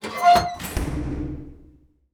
door_start.ogg